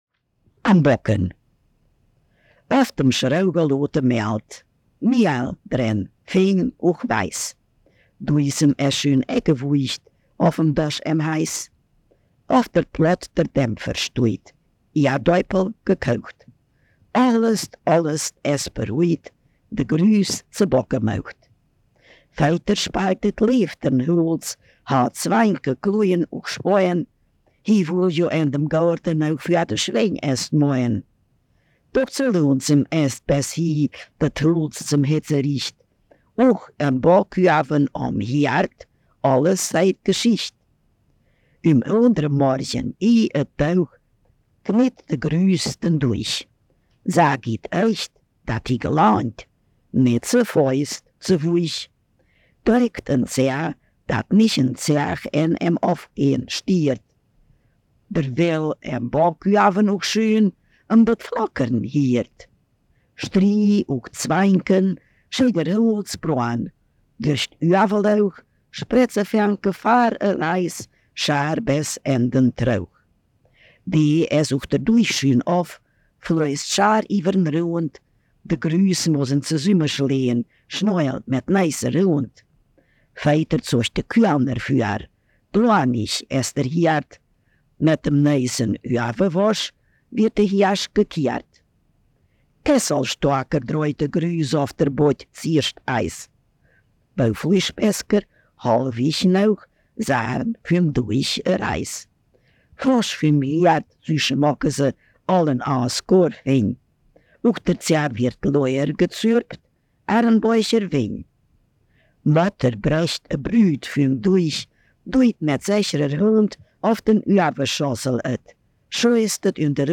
Ortsmundart: Denndorf